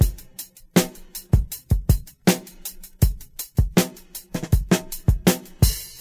• 106 Bpm Drum Beat F# Key.wav
Free drum loop sample - kick tuned to the F# note. Loudest frequency: 2329Hz
106-bpm-drum-beat-f-sharp-key-av2.wav